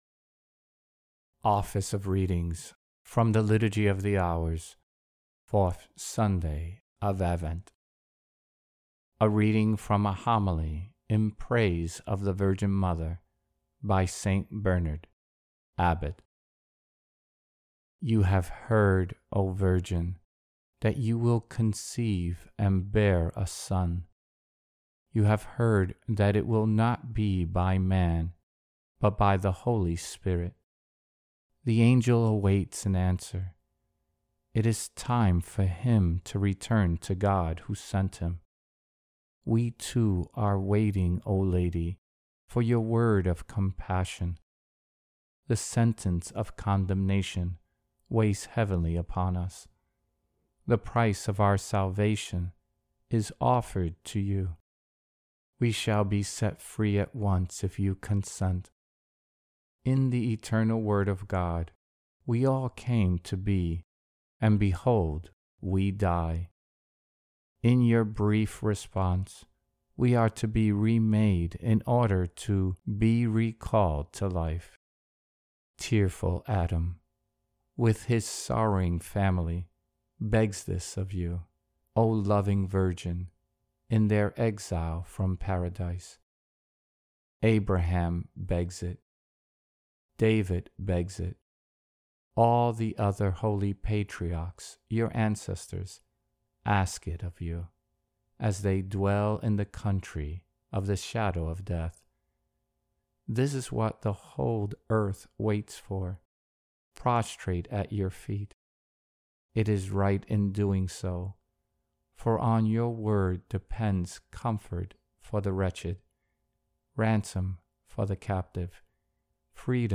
Office-of-Readings-4th-Sunday-of-Advent.mp3